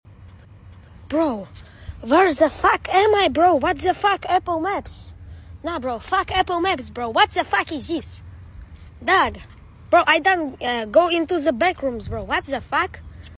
GIGGLINGG [ SORRY FOR NOT sound effects free download